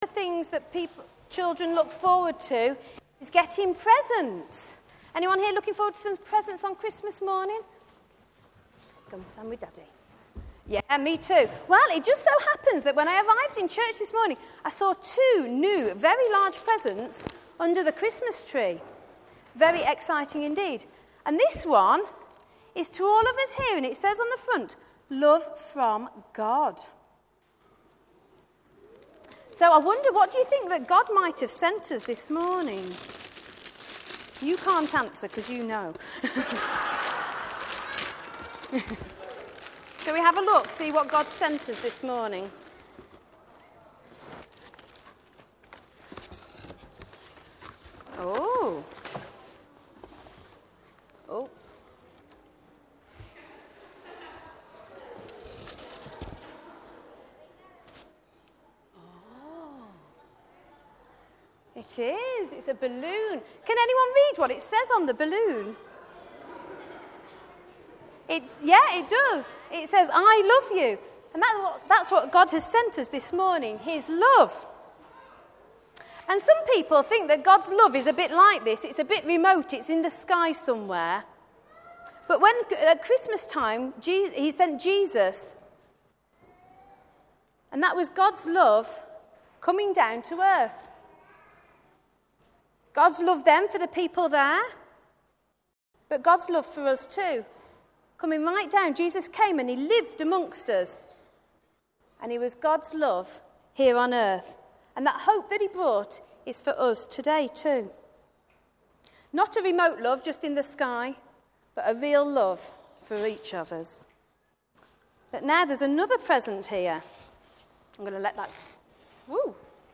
Talk
10:30 Morning Worship, St John's service